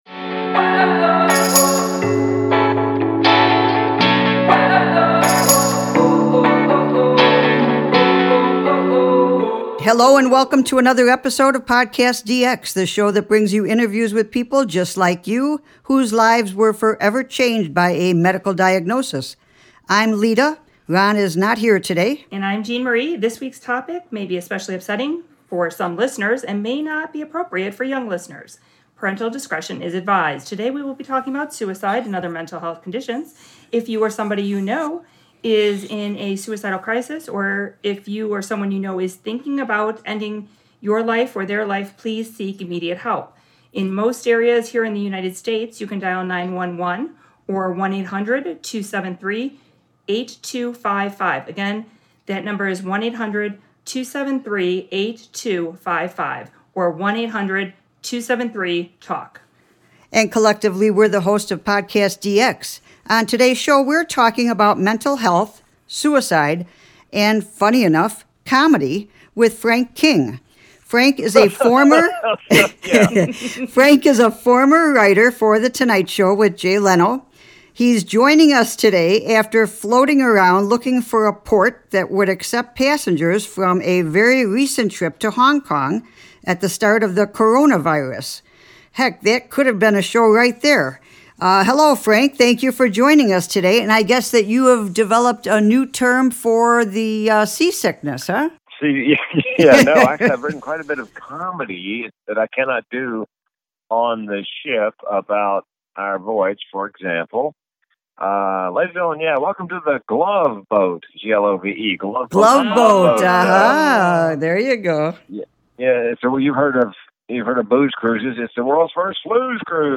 During the brief break he called in from Cambodia!